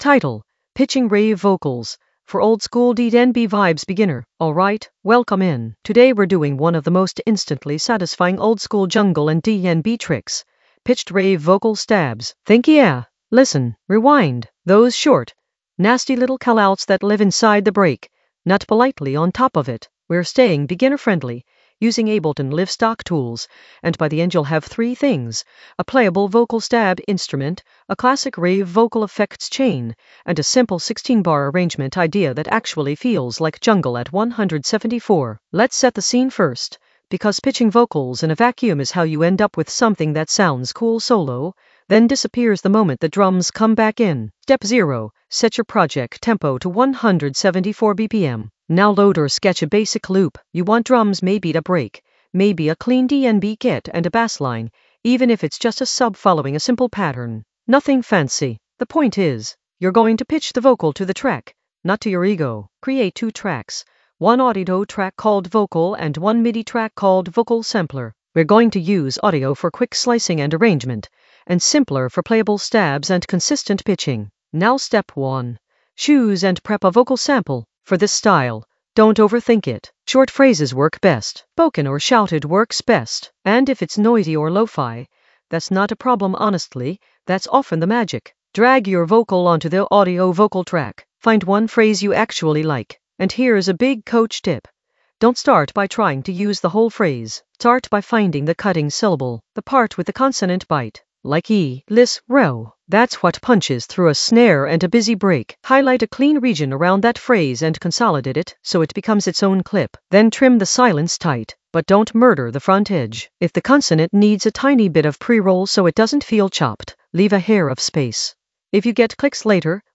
Narrated lesson audio
The voice track includes the tutorial plus extra teacher commentary.
An AI-generated beginner Ableton lesson focused on Pitching rave vocals: for oldskool DnB vibes in the Sampling area of drum and bass production.